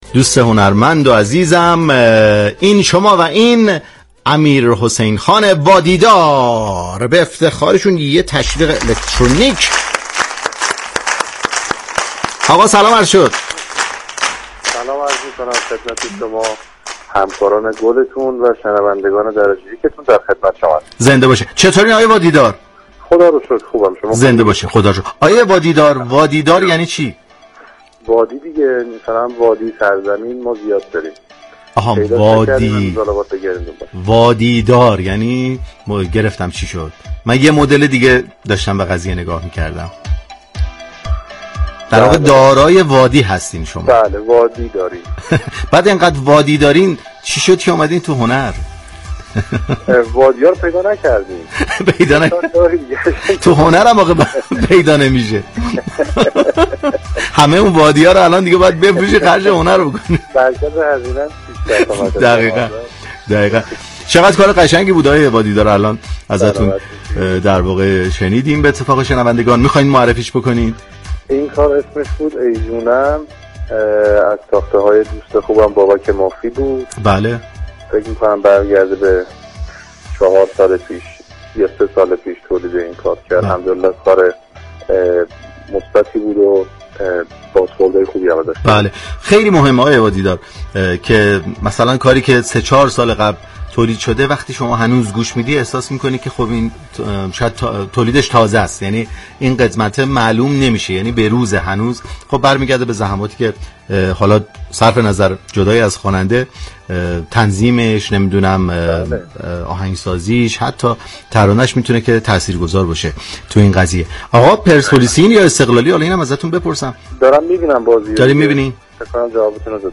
رادیو صبا در برنامه موسیقی محور «شیش و هفت» به گفتگو با هنرمندان و خوانندگان خوب كشورمان می پردازد.